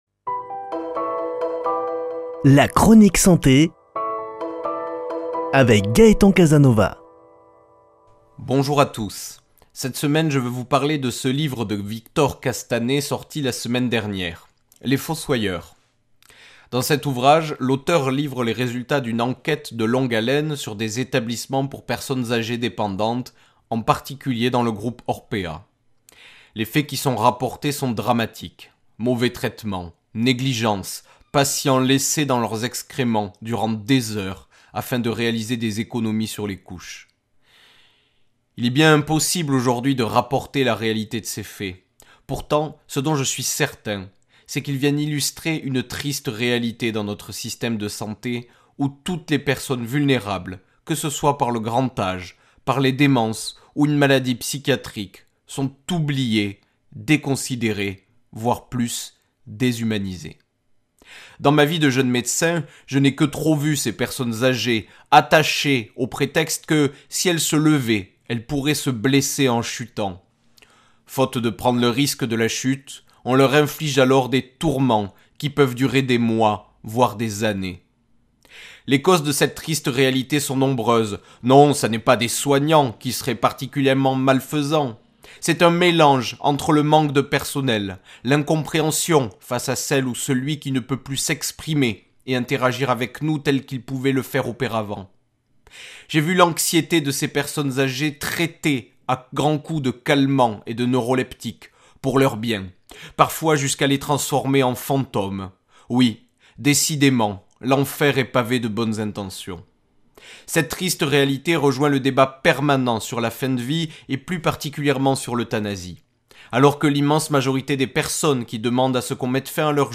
Chronique santé